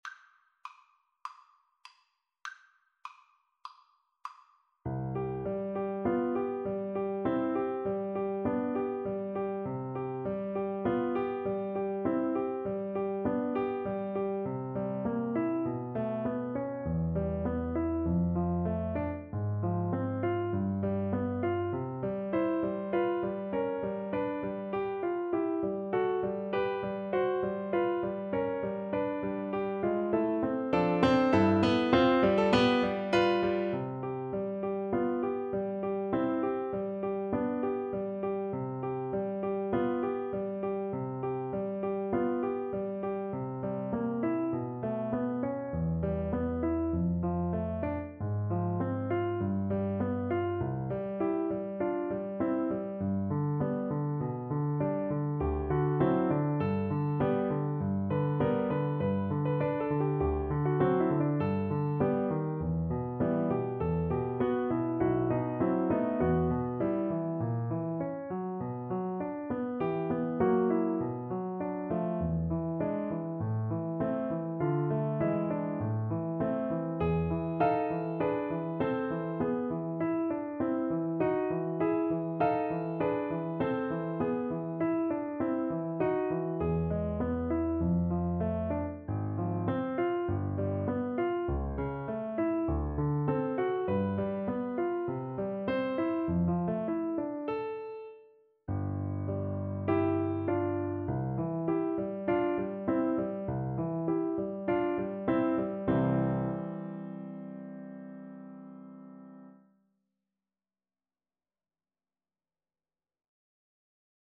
4/4 (View more 4/4 Music)
C major (Sounding Pitch) (View more C major Music for Flute )
Classical (View more Classical Flute Music)